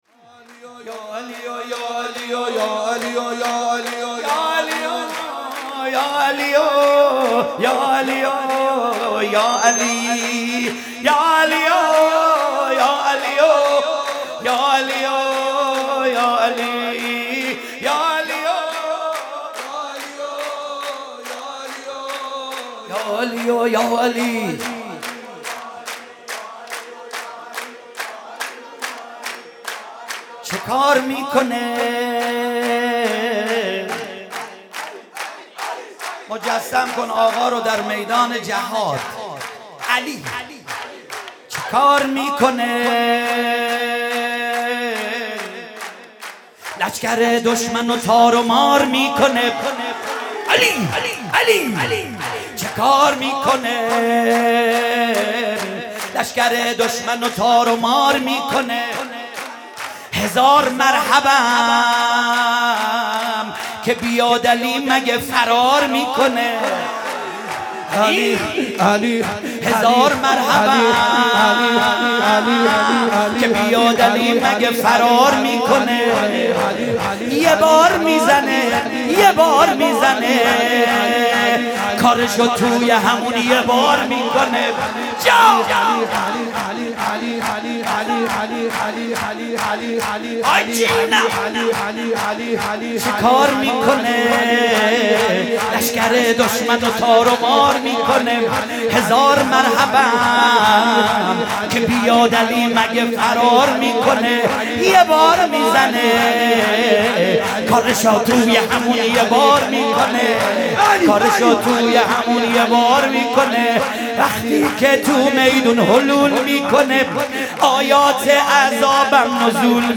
مراسم میلاد حضرت علی علیه السلام در حسینیه آیت الله مرعشی نجفی | هیئت ثارالله قم